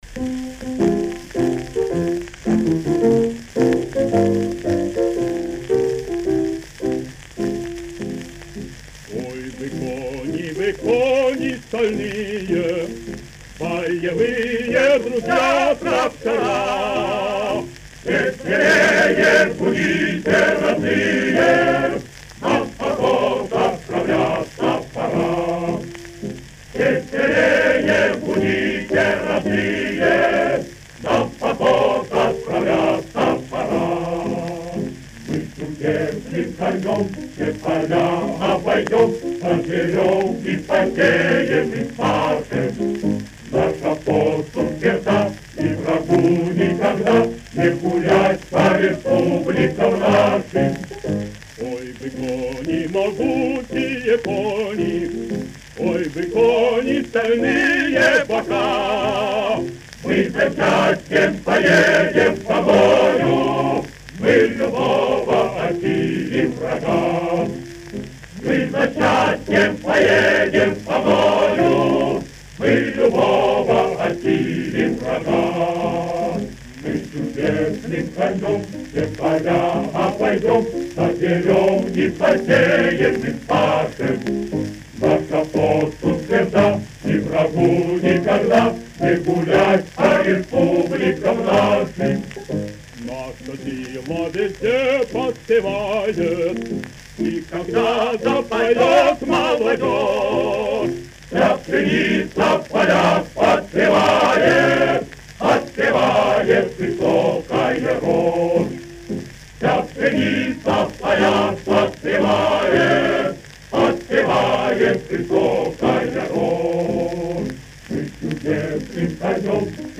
Марши
Прекрасный вариант исполнения довоенной массовой песни.